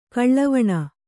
♪ kaḷḷavaṇa